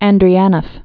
(ăndrē-ănəf, -ôf, ändrēnəf)